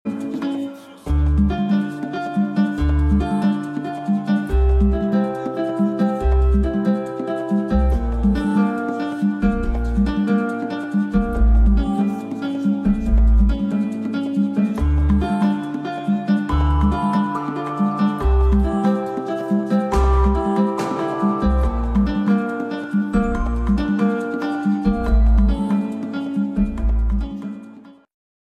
instrumentale